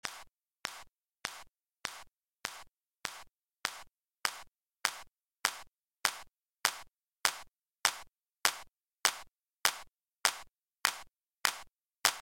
Reduce the attack time to the shortest possible, then creep it up so that ‘pop’ starts to come through like this:
You should be able to really hear that ‘smack’ coming out.